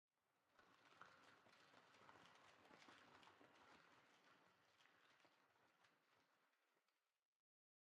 Minecraft Version Minecraft Version 25w18a Latest Release | Latest Snapshot 25w18a / assets / minecraft / sounds / block / pale_hanging_moss / pale_hanging_moss5.ogg Compare With Compare With Latest Release | Latest Snapshot
pale_hanging_moss5.ogg